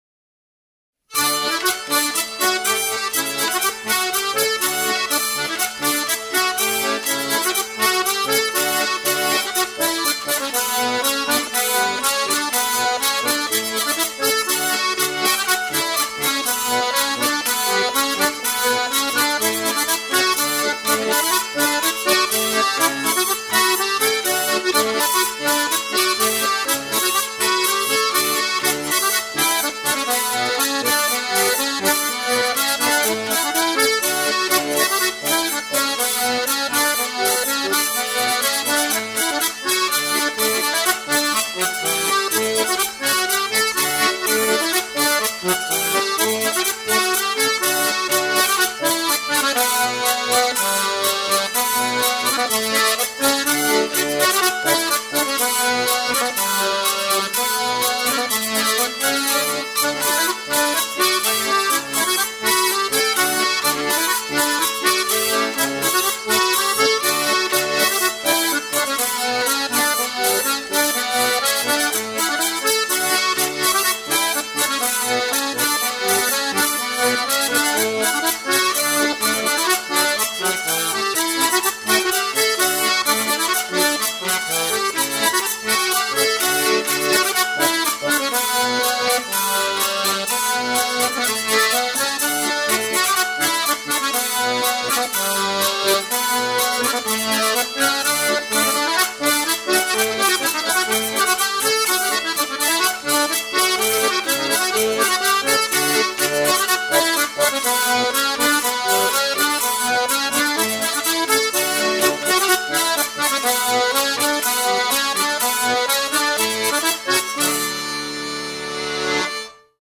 joueur d'accordéon
Chants brefs - A danser
danse : polka